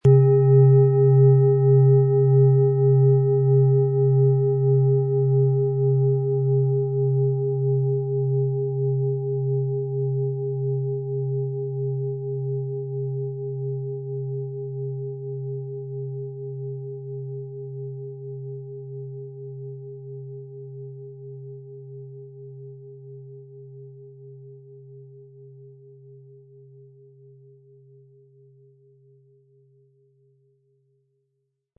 Wie klingt diese tibetische Klangschale mit dem Planetenton DNA?
Durch die traditionsreiche Herstellung hat die Schale stattdessen diesen einmaligen Ton und das besondere, bewegende Schwingen der traditionellen Handarbeit.
MaterialBronze